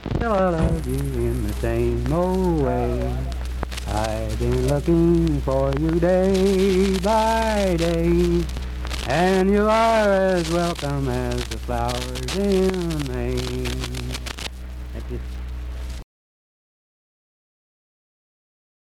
Unaccompanied vocal performance
Verse-refrain 1(2).
Voice (sung)